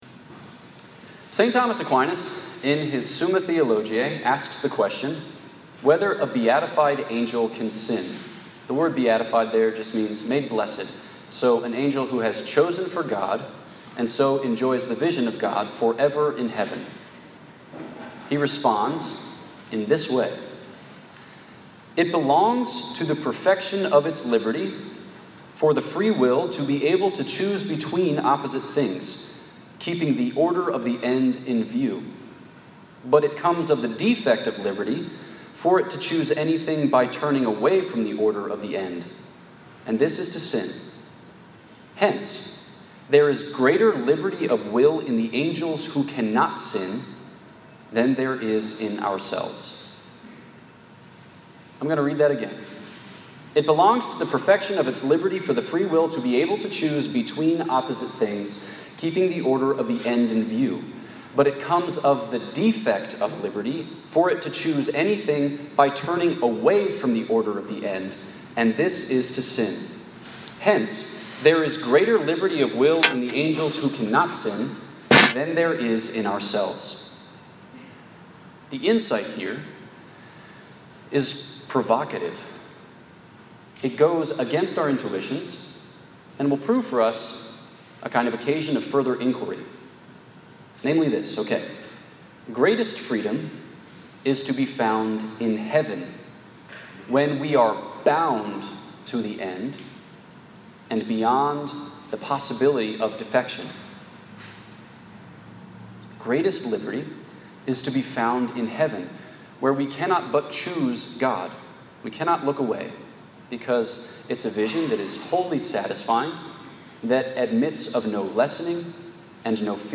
This lecture was given at Louisiana State University on 4 November 2019.